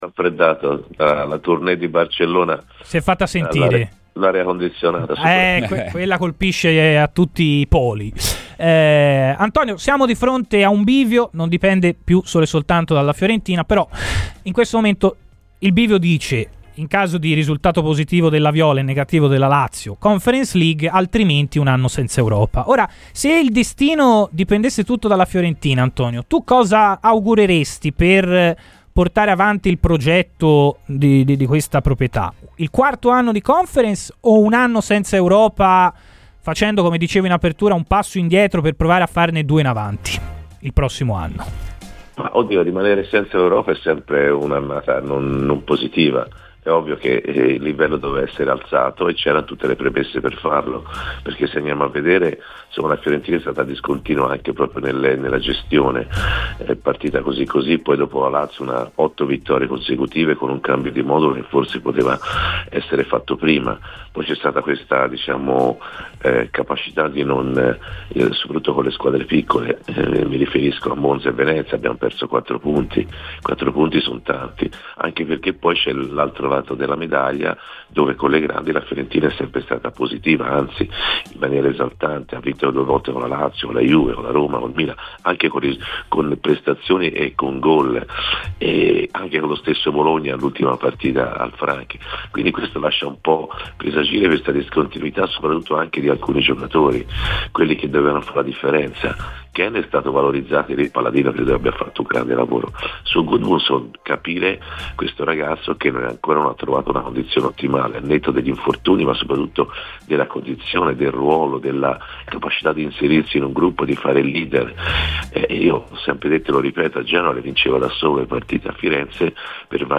L'ex centrocampista della Fiorentina, oggi opinionista televisivo, Antonio Di Gennaro è intervenuto a Radio FirenzeViola nel corso della trasmissione "Chi Si Compra" per analizzare il momento della formazione gigliata.